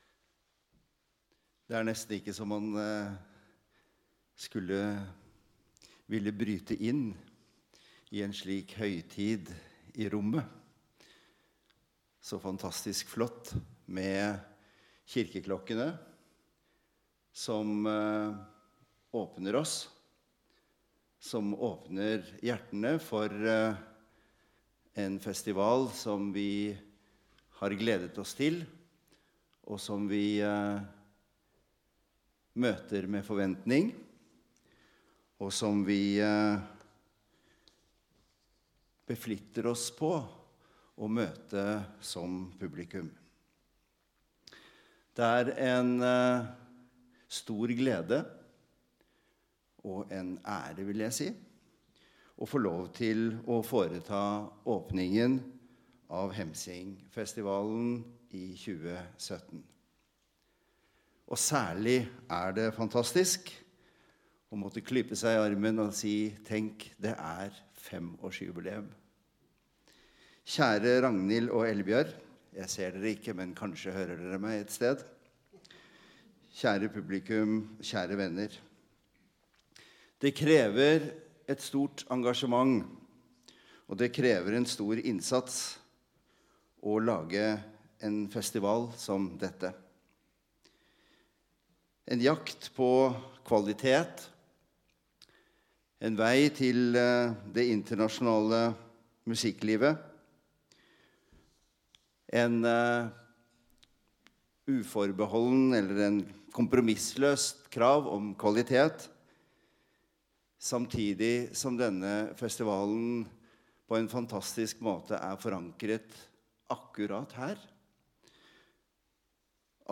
Tale ved åpningen av Hemsingfestivalen
Stortingspresident Olemic Thommessens tale i Valdres 23. februar 2017.